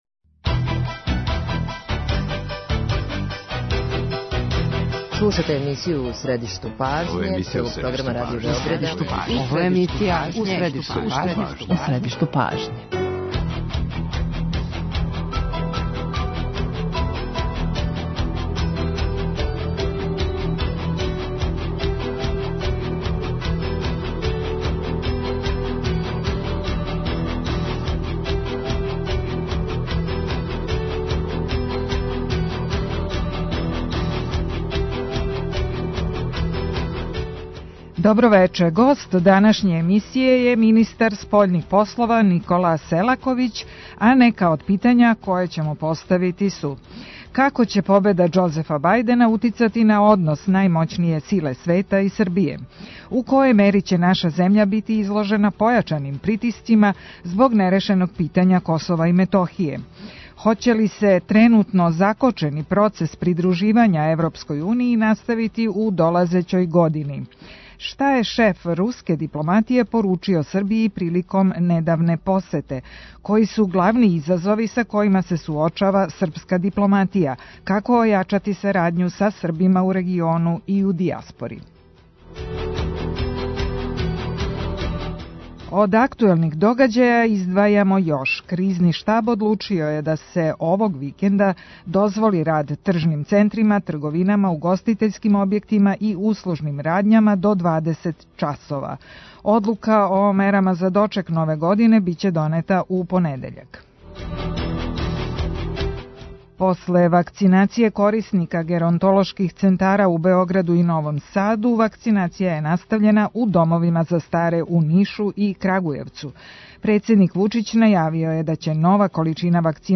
Гост емисије је министар спољних послова Никола Селаковић.